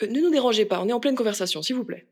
VO_ALL_EVENT_Trop proche de la cible_03.ogg